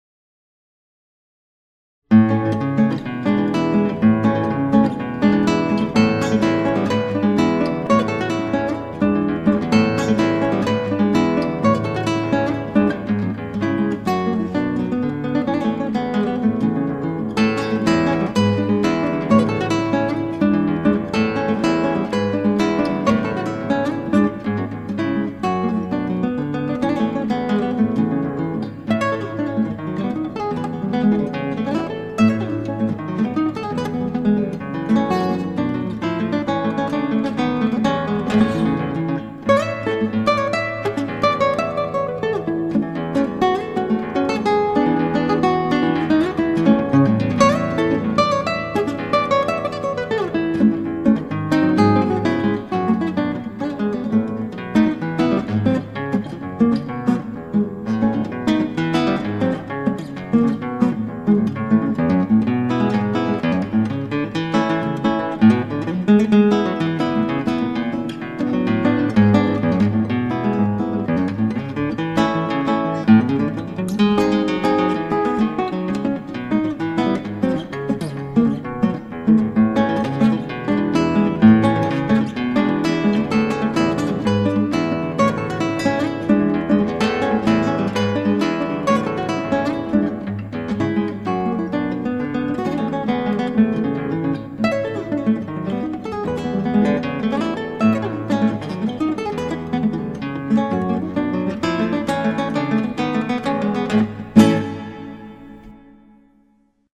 クラシックギター　ストリーミング　コンサート
愉快な曲です。
三連なんだけど三連じゃないリズムで難しいんです。